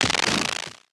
tesla-turret-beam-deflection-6.ogg